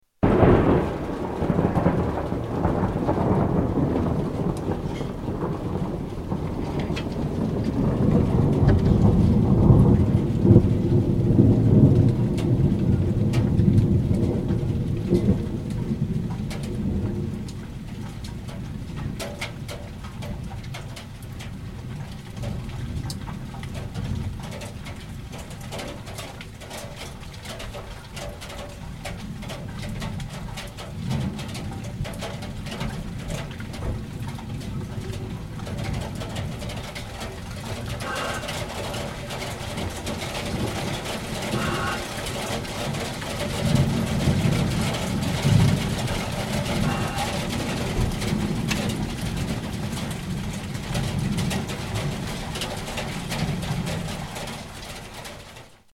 Rain falling at village of Drache